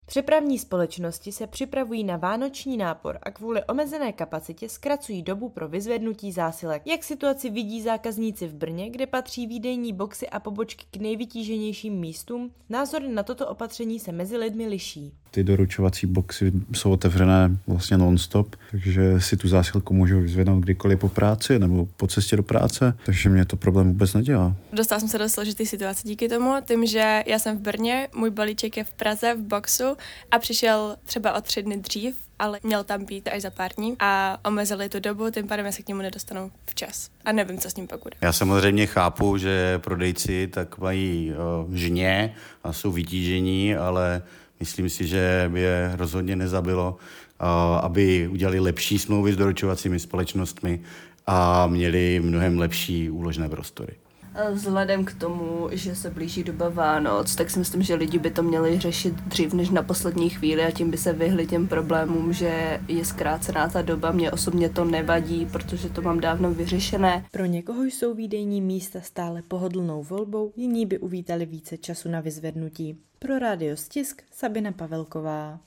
predělaná anketa.MP3